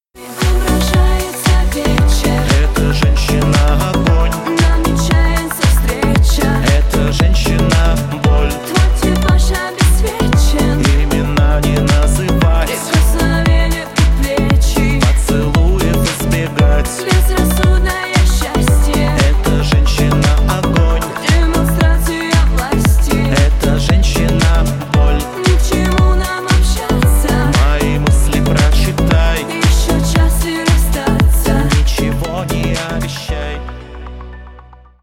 • Качество: 160, Stereo
поп
мощные
дуэт
русская попса